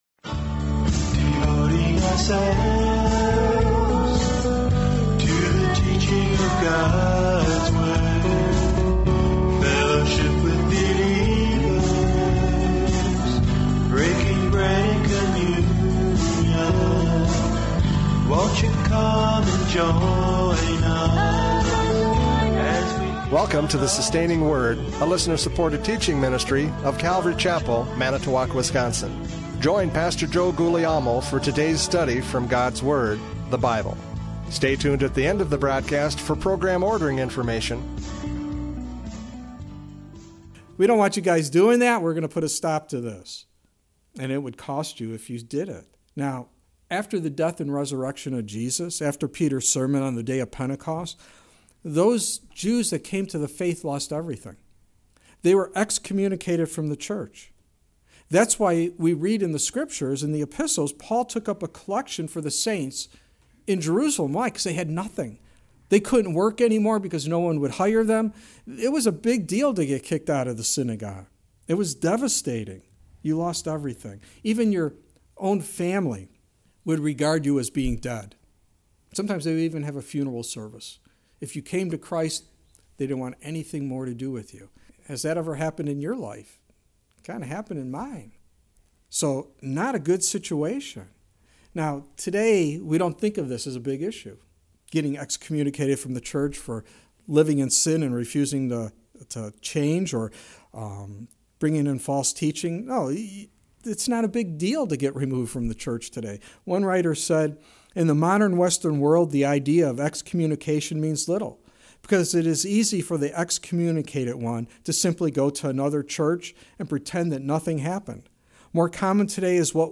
John 9:8-34 Service Type: Radio Programs « John 9:8-34 The Inquest!